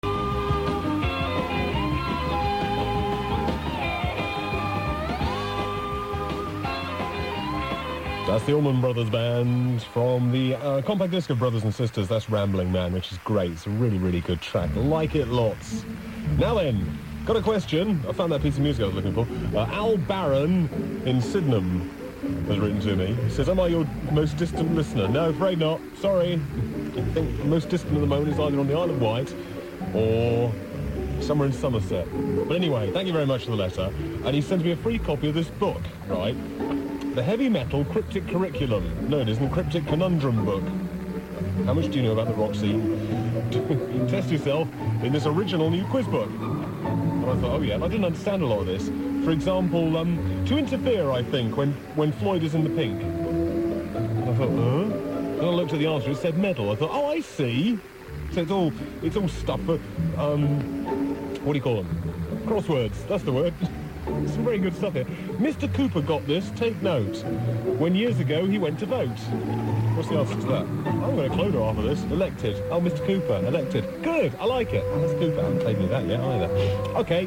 The Heavy Metal Cryptic Conundrum Book, (of which I am still deeply embarrassed), actually got a favourable mention here. He read out a couple of riddles too!